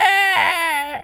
monkey_hurt_scream_02.wav